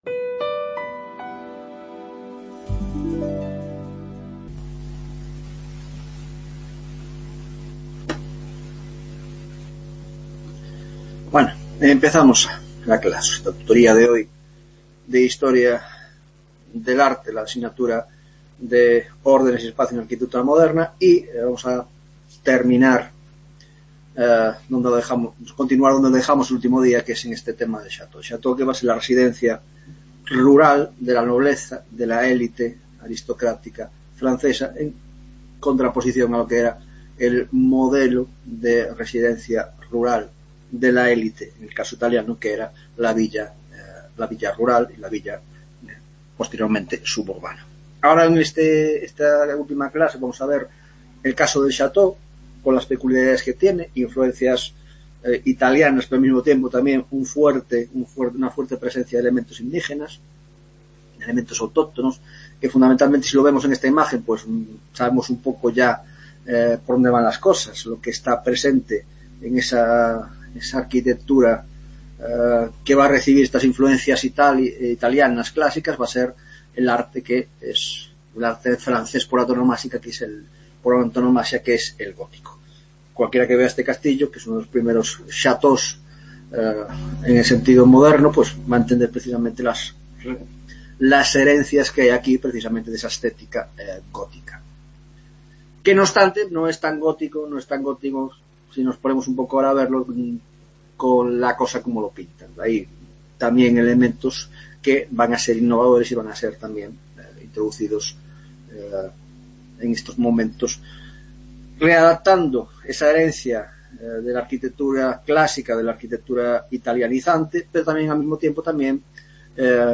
10ª Tutoría de Órdenes y Espacio en la Arquitectura Moderna - Tipologías Arquitectónicas: El Chateau y los Palacios Reales en Francia; El Jardín y Otras Tipologias (Hospital, Biblioteca y Teatro)